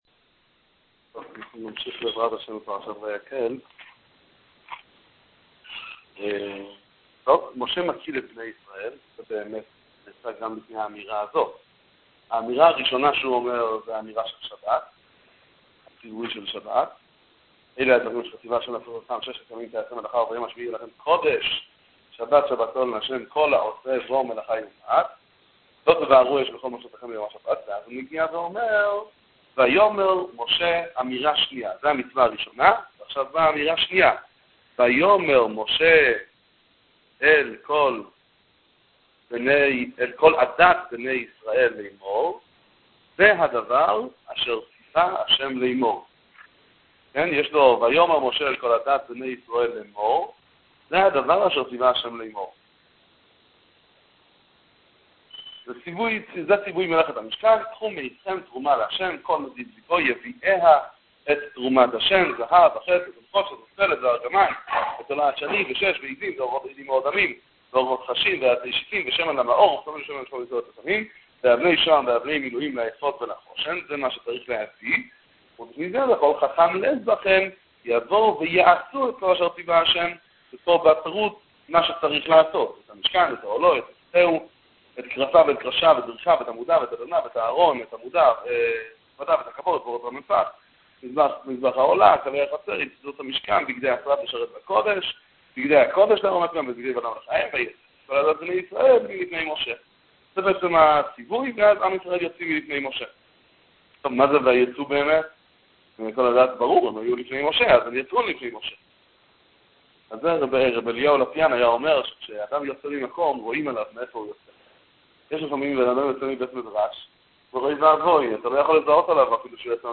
שיעורי תורה דברים קצרים ונחמדים עם סיפורים על פרשת השבוע